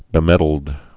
(bĭ-mĕdld)